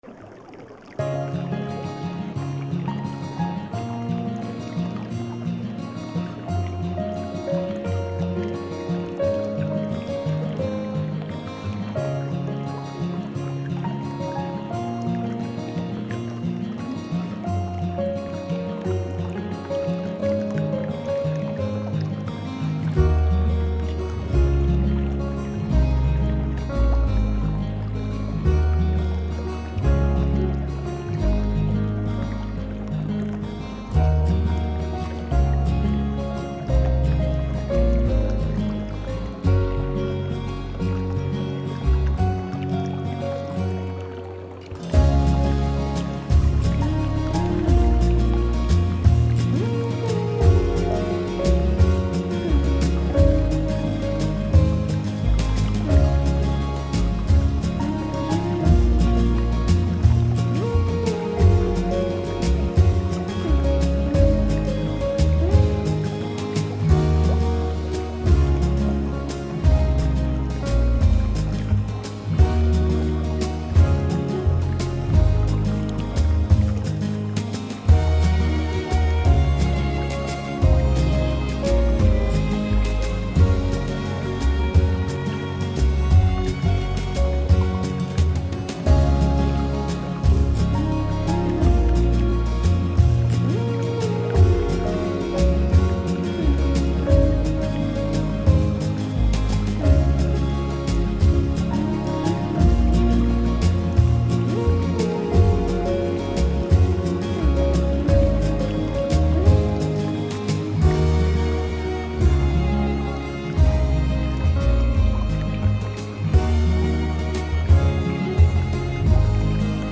MYSTISCHE GEIST & SEELEN-ENTSPANNUNG: Zen-Garten-Wasserberuhigung mit Rauschen